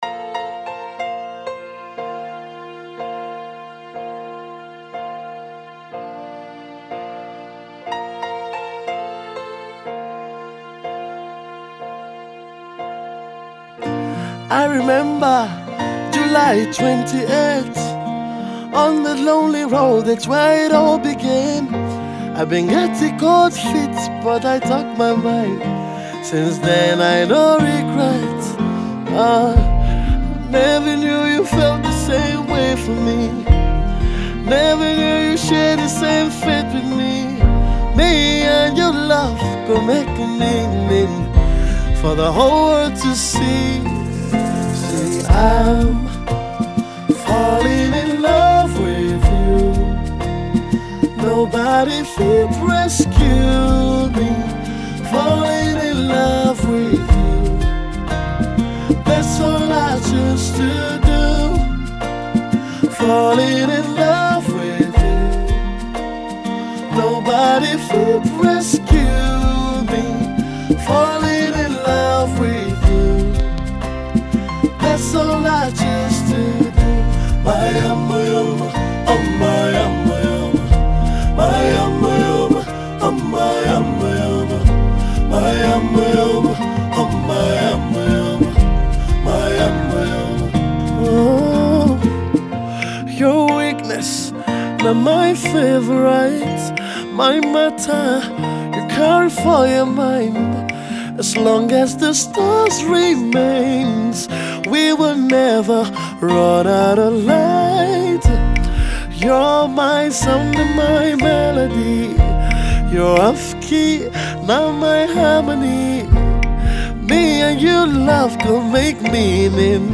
Afro-soul and RnB